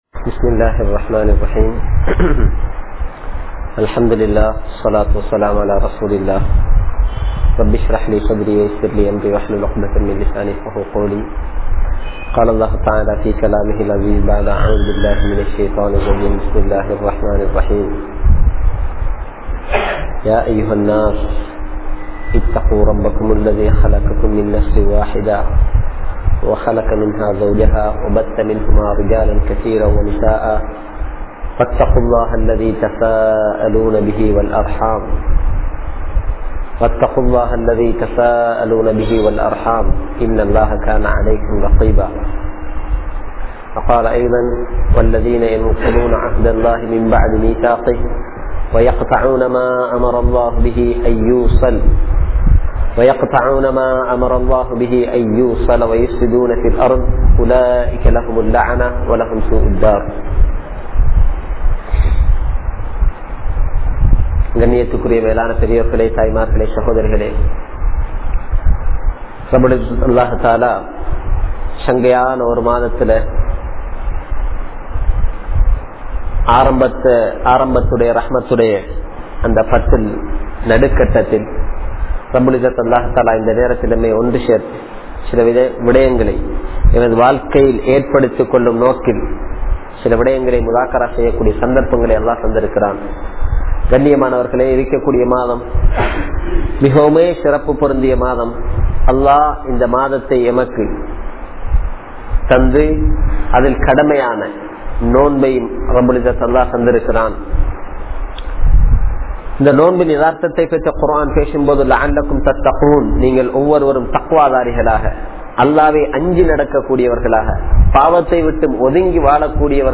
Kudumba Uravinarhalai Mathiungal (குடும்ப உறவினர்களை மதியுங்கள்) | Audio Bayans | All Ceylon Muslim Youth Community | Addalaichenai